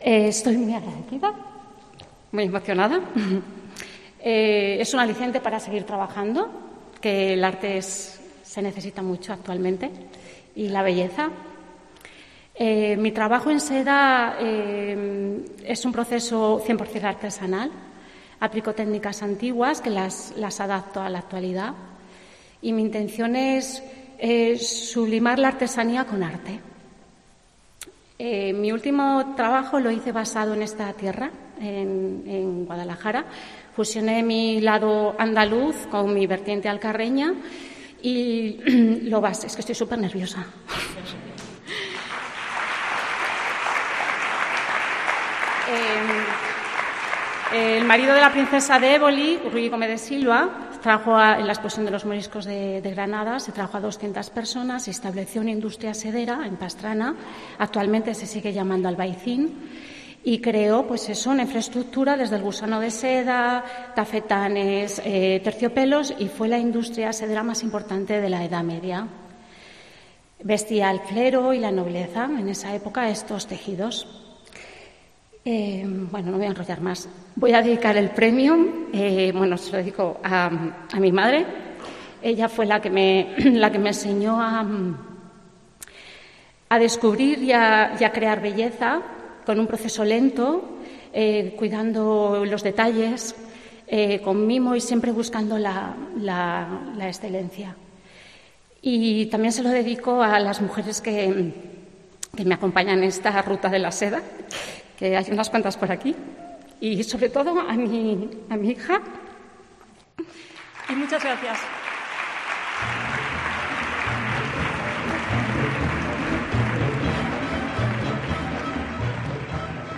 Discurso